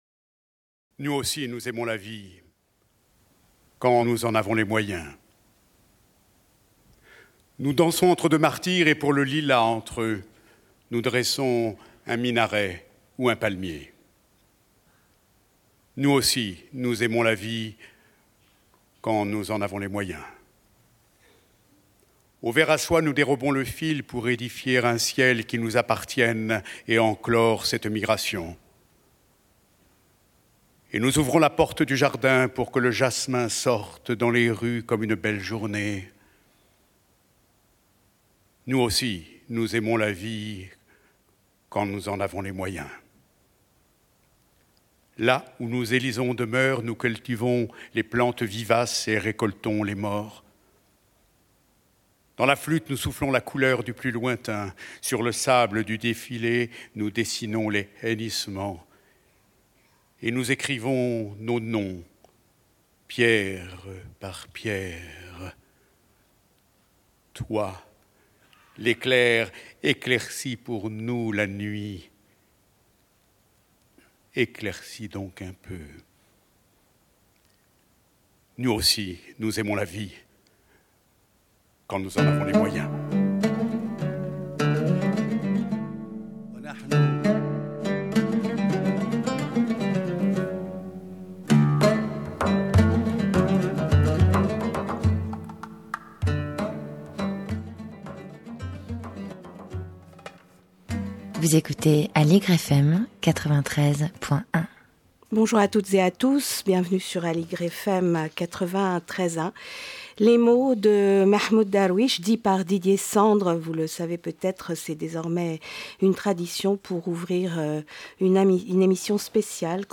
une émission spéciale
en direct de Gaza